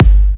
edm-kick-40.wav